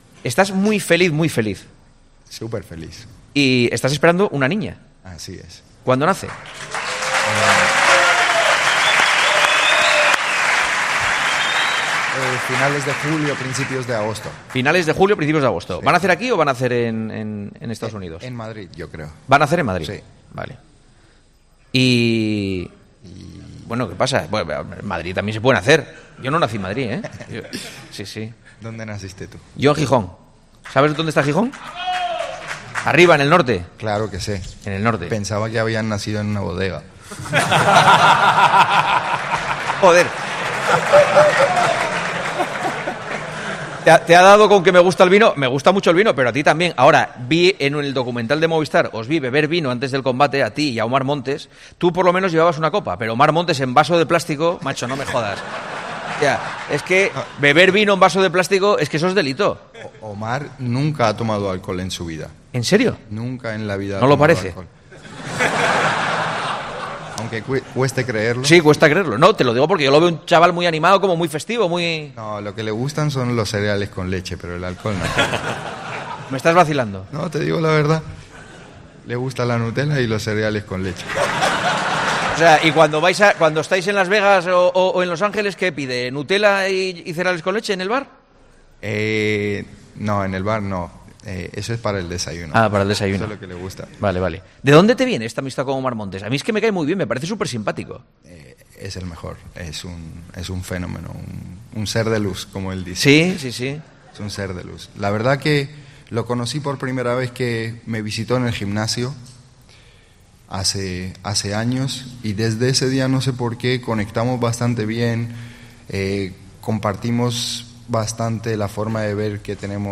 'El Matador' fue recibido entre gritos y una sonora ovación por el Teatro Principal de Alicante antes de someterse a las preguntas de Juanma Castaño.
"En Madrid también se puede nacer", afirmó Juanma ante algunos abucheos del público presente en el Teatro Principal de Alicante.